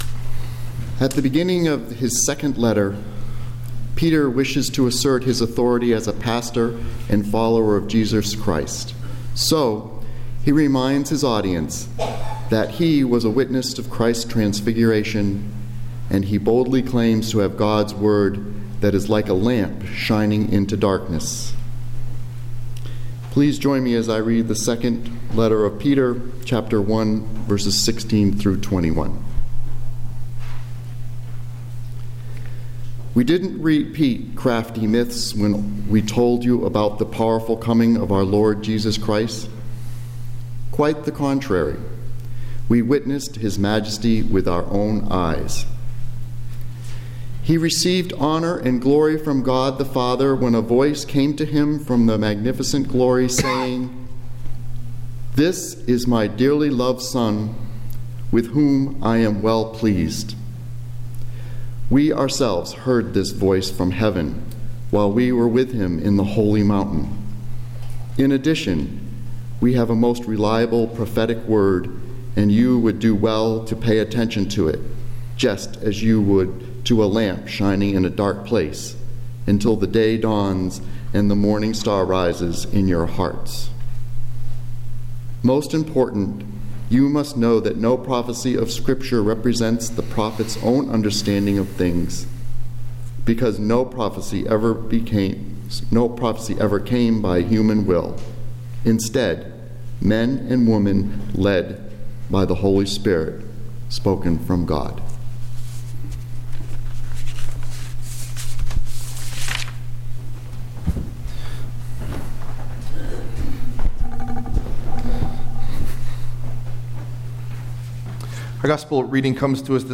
Message Delivered at: The United Church of Underhill (UCC and UMC)